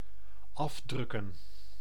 Ääntäminen
IPA: /ˈɑvˌdrʏkə(n)/